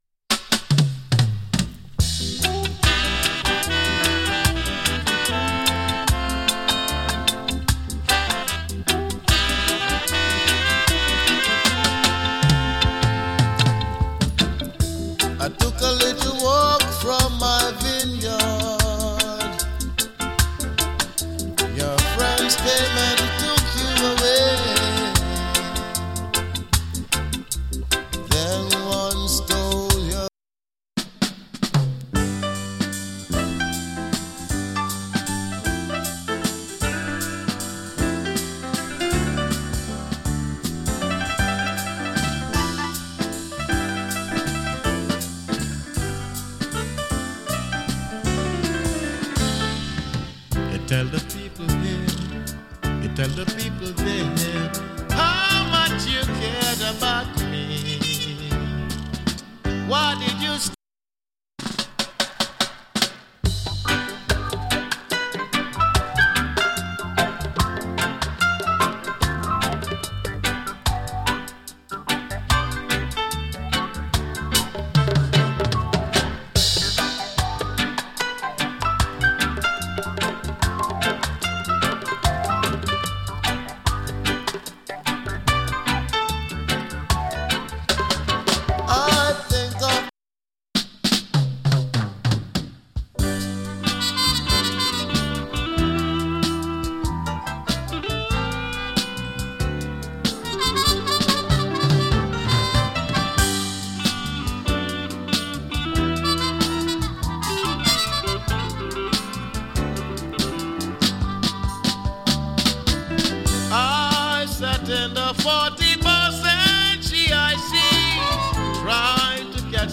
チリ、ジリノイズわずかに有り。
79年リリースの ROOTS ROCK ALBUM !